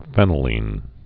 (fĕnə-lēn, fēnə-)